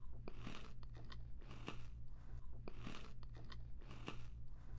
吃薯片
描述：nom nom nom，打破了我的饮食只是为了记录一些游戏音频XDi告诉你，咸甘草芯片是一个相当的经验.. 但是啊三次咀嚼三个芯片的声音，减慢了一点，以获得更多的视频游戏效果
声道立体声